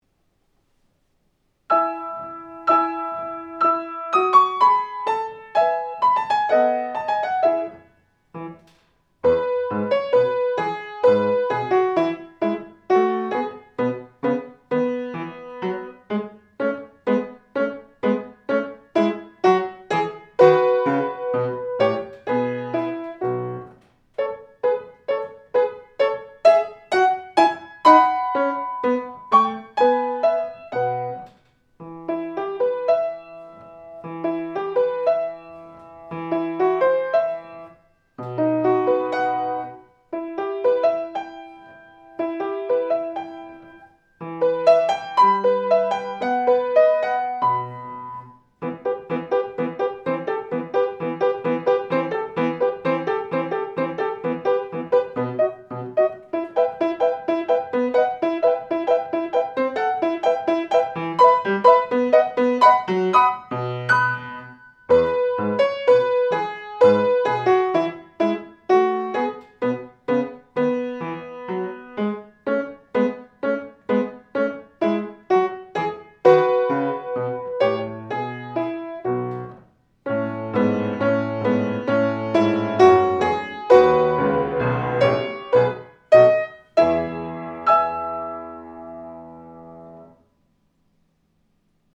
⭐予選審査では、下記の課題曲の伴奏をご利用いただくことも可能です。
青い眼の人形前奏２小節